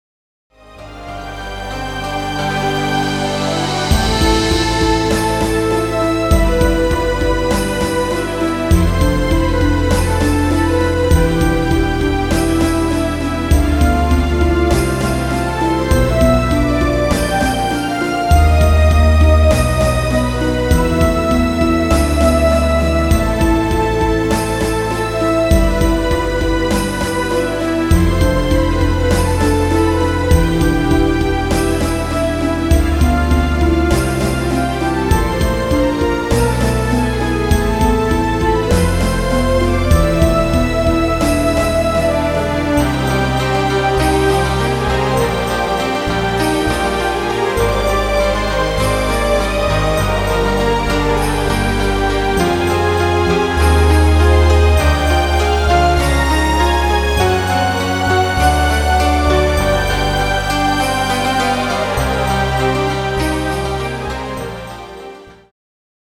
ゲーム音楽を原曲重視でアレンジしています。